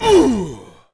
Sound / sound / monster / barbarian_boss / fall_1.wav
fall_1.wav